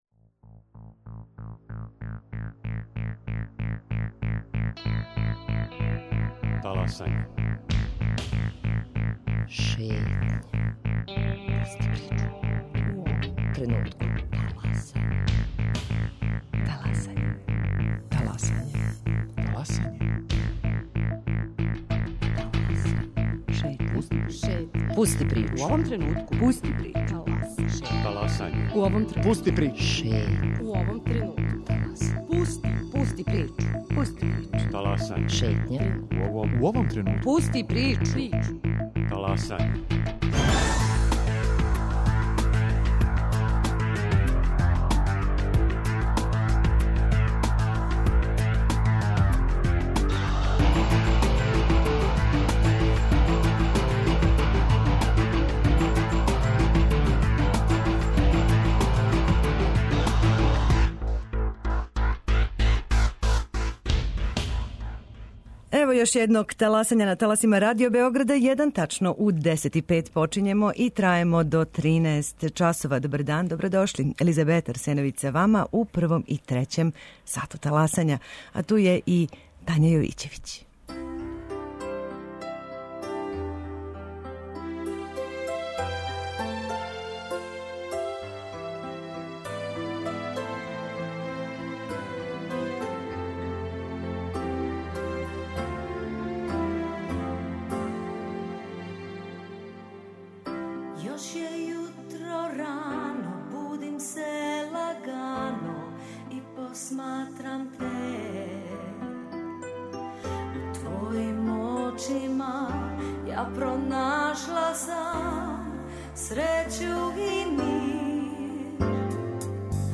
Још више детаља чућемо од наше гошће